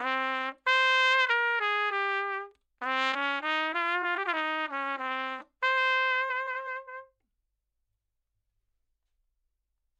小号循环弗里吉亚型
它有96个BMP，音阶是CPhrygian(C, Db,Eb, F, G,Ab,Bb).
Tag: 96 bpm Ethnic Loops Brass Loops 1.68 MB wav Key : C